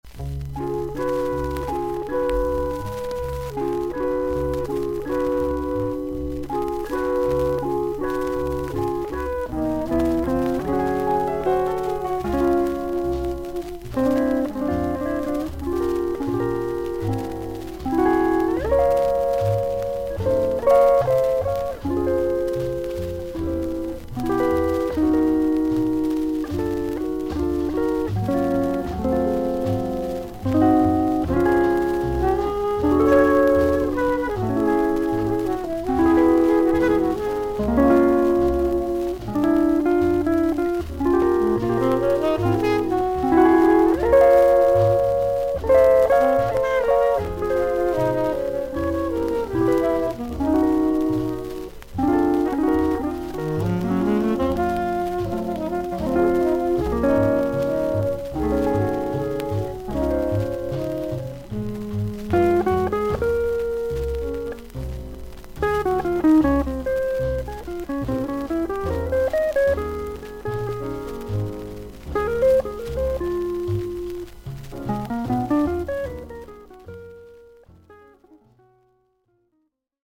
少々軽いパチノイズの箇所あり。レコードの素材の因ると思われるさわさわしたサーフィス・ノイズがあります。
ジャズ・ギタリスト。